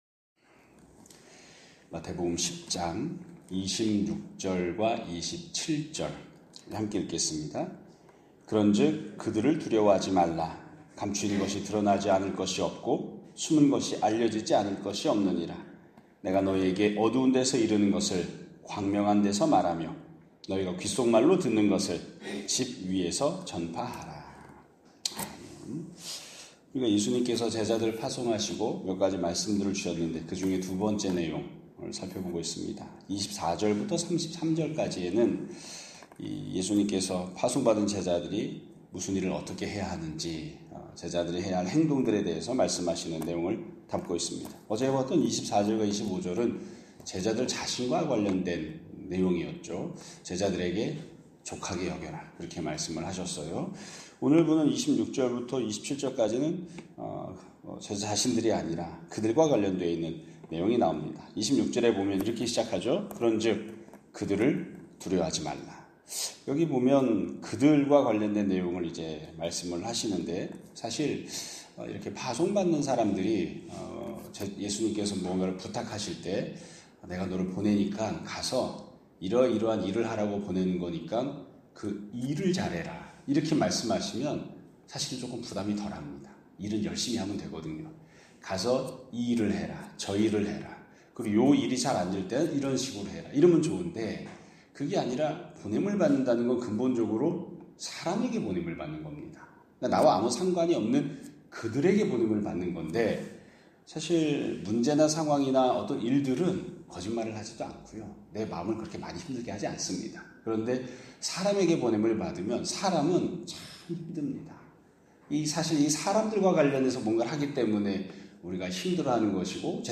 2025년 8월 12일 (화요일) <아침예배> 설교입니다.